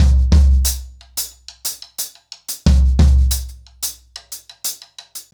InDaHouse-90BPM.35.wav